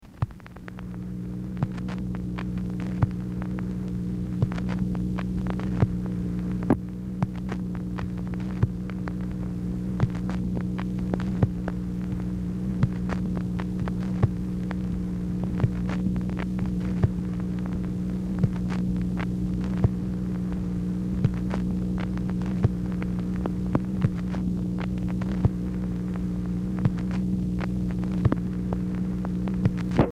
MACHINE NOISE
Oval Office or unknown location
ORIGINAL BELT DAMAGED
Telephone conversation
Dictation belt